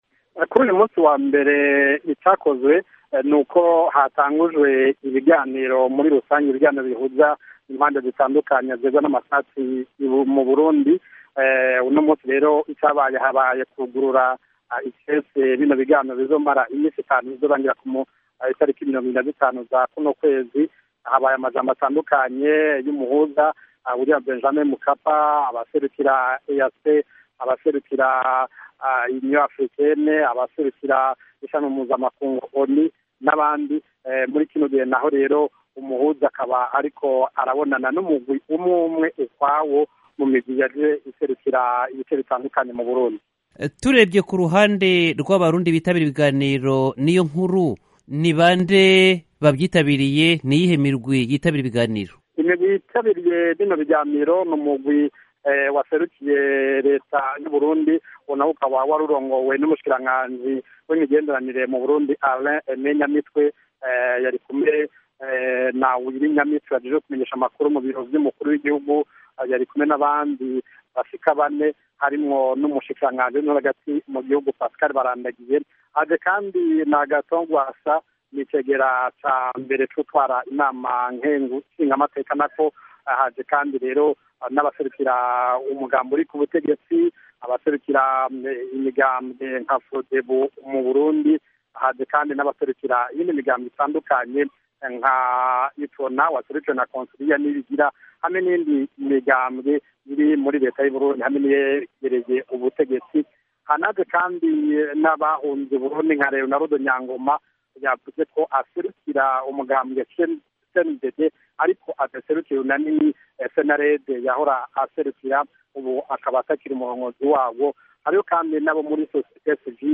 Kuri iyi nkuru, Ijwi ry’Amerika ryitabaje umunyamakuru wa Radiyo Bonesha FM y’I Bujumbura